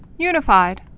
u·ni·fied field theory
(ynə-fīd)